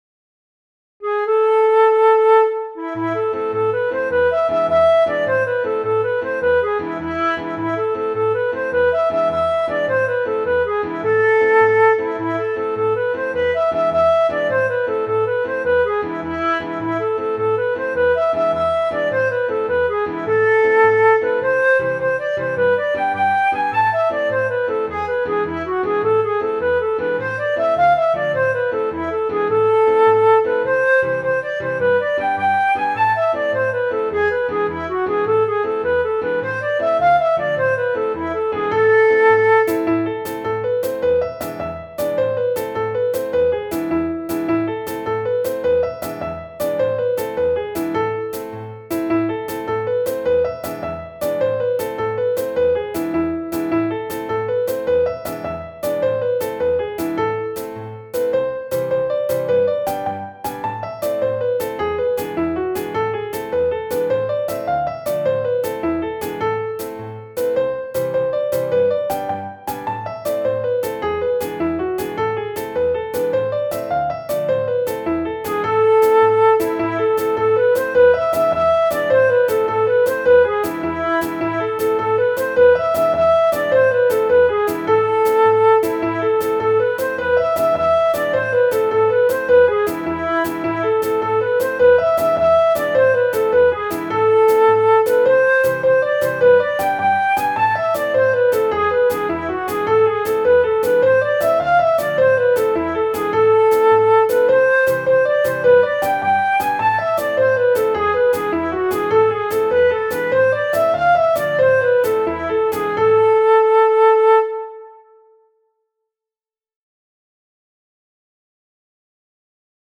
Folk dance Music Any 32 bar jig or reel. Here is an appropriate track which is at 104bpm.